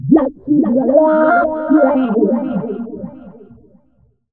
VOX FX 1  -L.wav